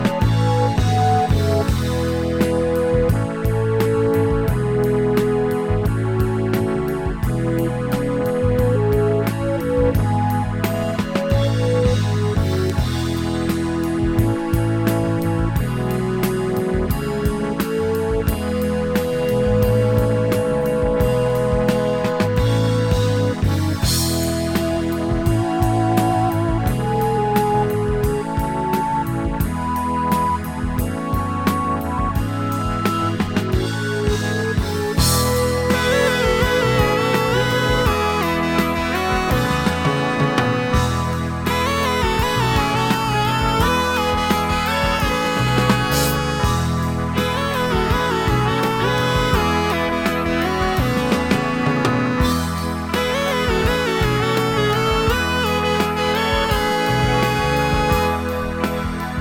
少しノホホンとしたゆったり感が堪らないプログレ。